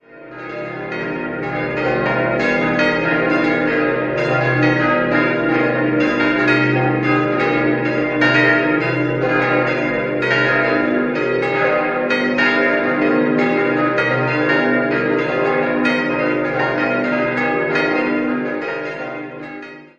Idealsextett: c'-es'-f'-as'-b'-c'' Die sechs Glocken wurden im Jahr 1947 von Johann Hahn in Landshut gegossen.